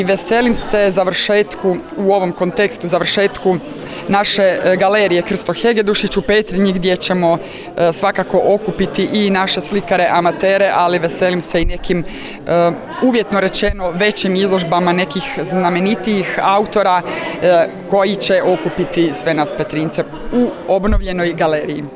U organizaciji Zajednice kulturno – umjetničkih udruga SMŽ u prostoru Strukovne škole u Sisku uručena su priznanja i zahvalnice sudionicima natječaja za književna i likovna djela autora s područja naše županije za 2022. i 2023. godinu.
Dokaz je to, ističe Komes, da Petrinja i dalje diše kulturu, te dodala